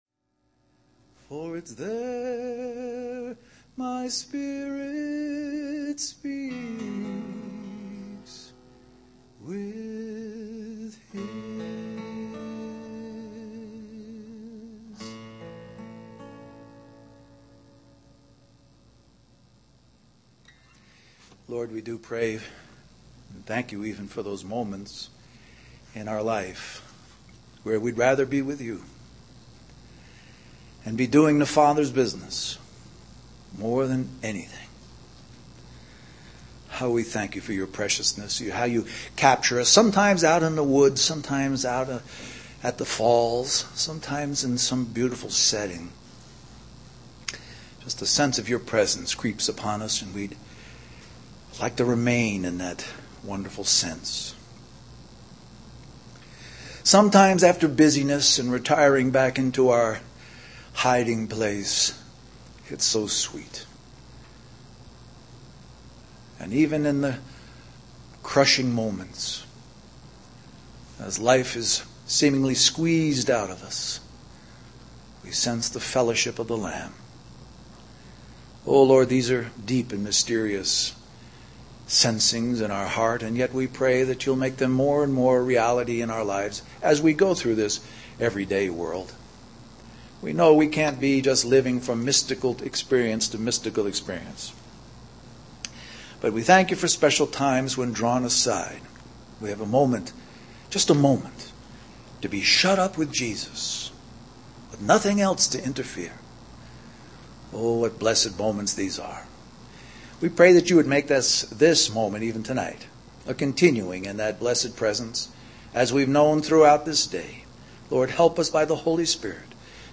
A collection of Christ focused messages published by the Christian Testimony Ministry in Richmond, VA.
Toronto Summer Youth Conference